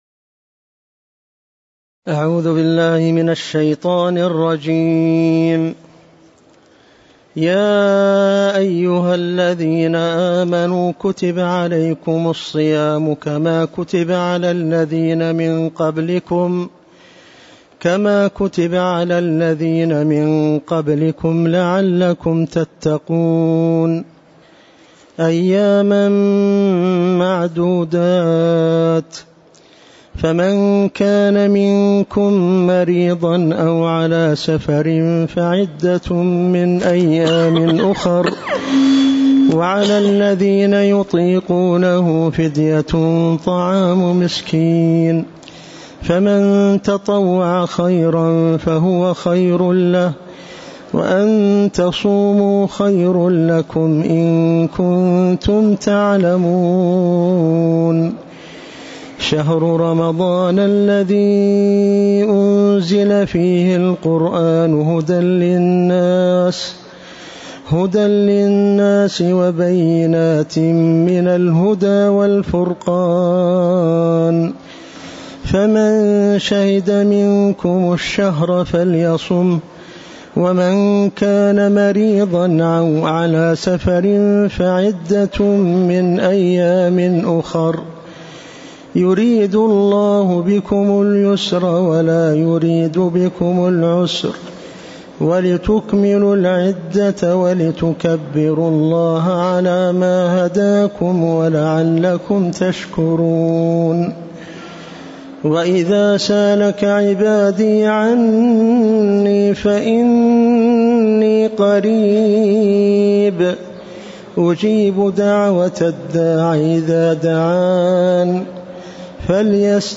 تاريخ النشر ٦ رمضان ١٤٤٣ المكان: المسجد النبوي الشيخ